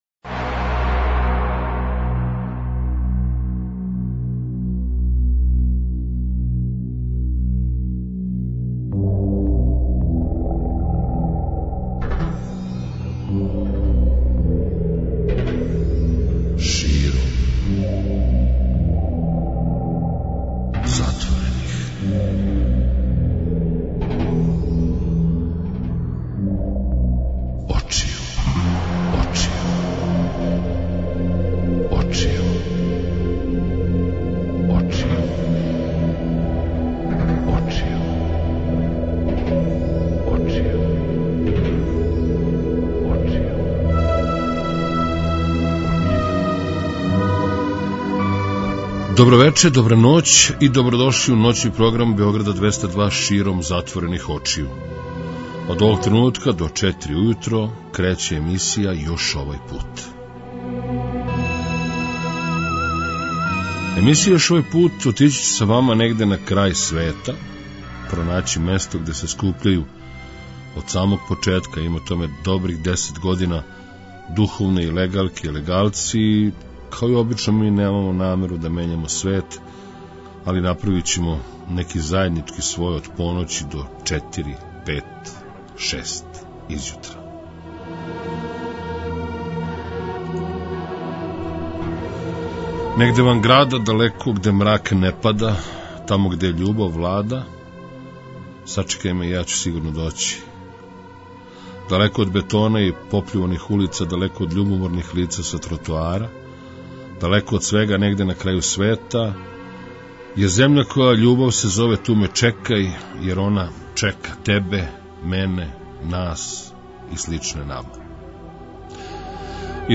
Уз музику која слика, пише, прича поделићемо најлепше емоције, давно заборављене речи и подсетити се да смо сви заједно живи, још увек, да се надамо јер то нико не може узети.